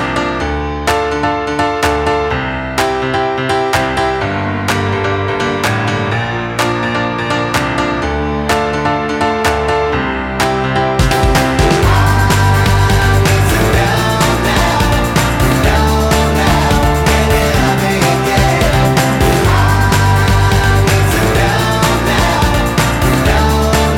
no Backing Vocals Dance 3:41 Buy £1.50